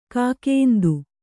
♪ kākēndu